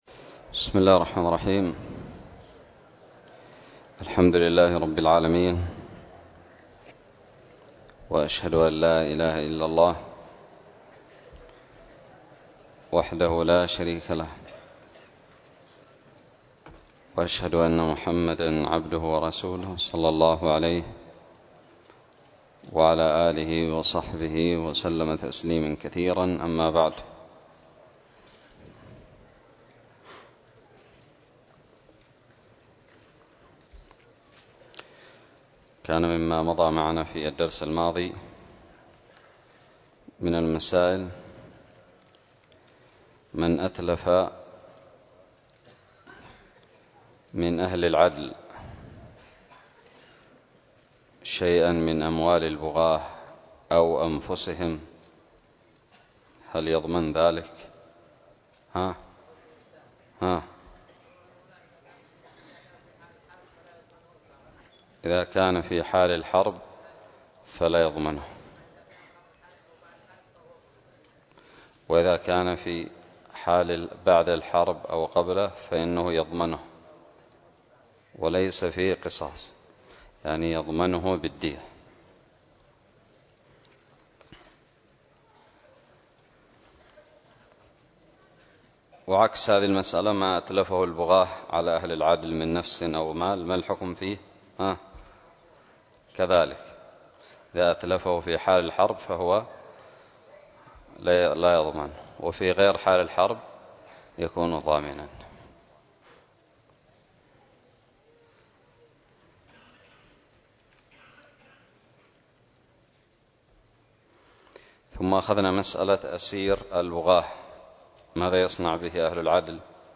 الدرس الرابع والخمسون من كتاب الجهاد من الدراري
ألقيت بدار الحديث السلفية للعلوم الشرعية بالضالع